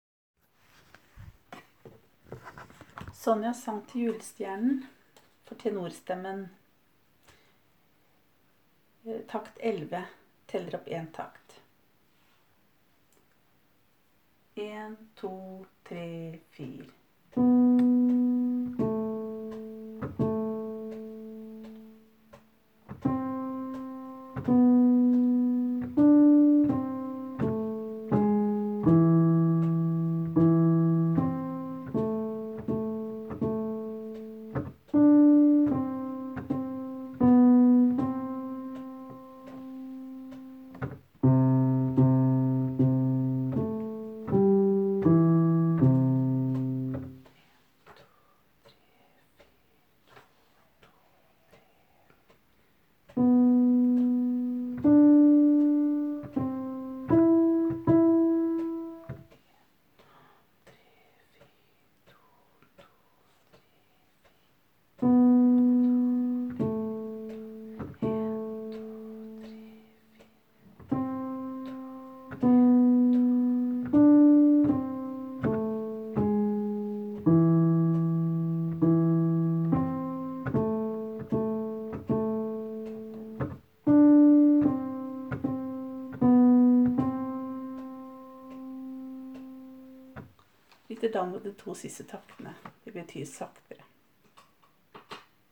Jul 2017 Tenor (begge konserter)